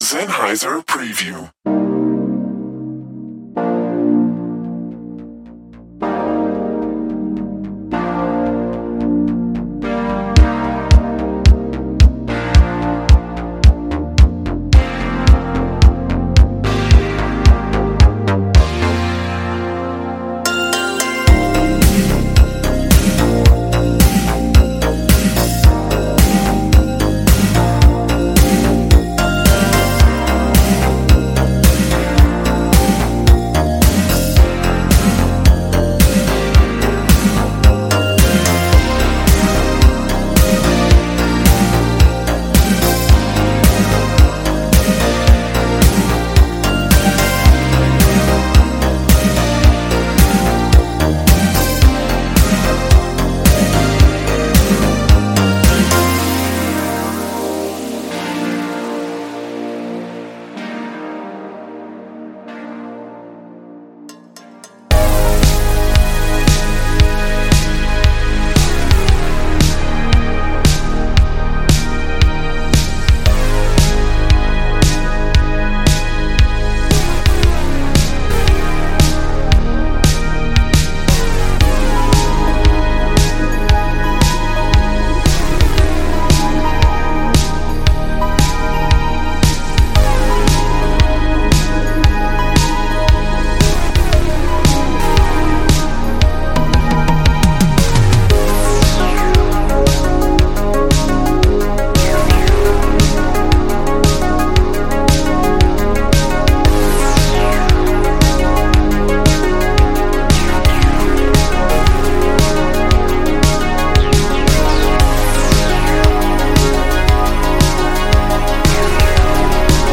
......::::::预览PRODUCT DEMO/PREViEW ::::::......
Tempo - 92bpm - 115bpm